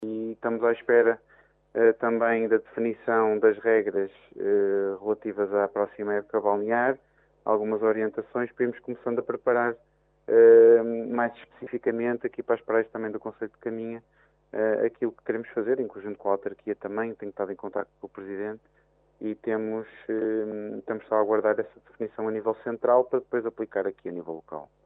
Capitão do Porto de Caminha a dar conta do que se pode e não pode fazer nas praias enquanto se aguarda o anúncio de regras por parte do Governo.